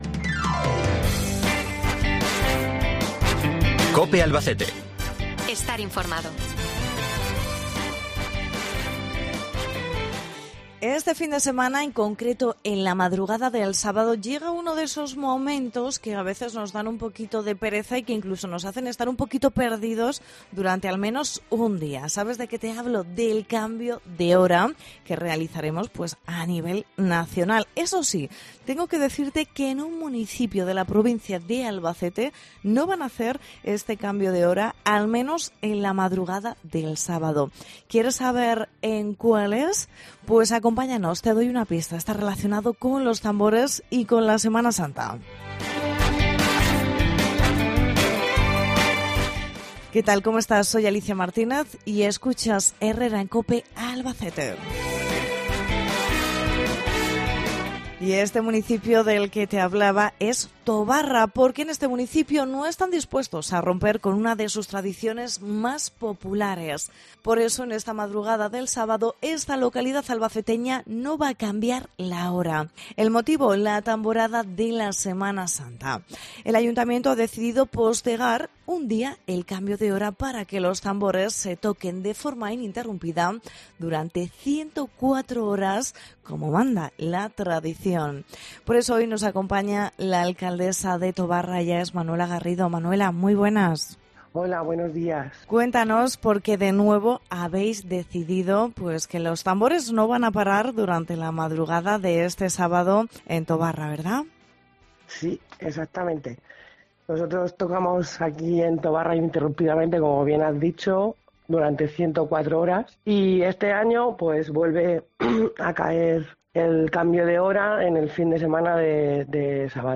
Hablamos con Manuela Garrido, alcaldesa de Tobarra